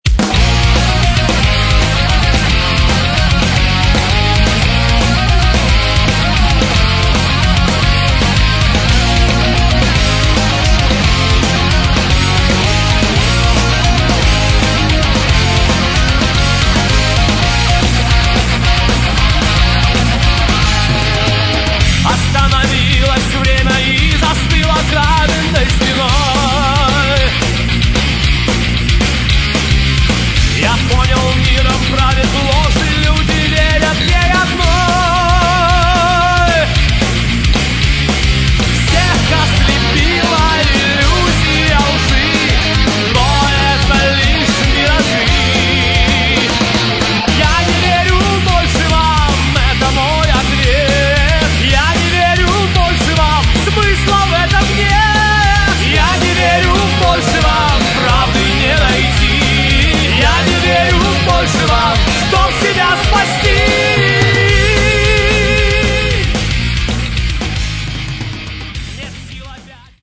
Metal
гитары
вокал, клавишные, флейта
бас
ударные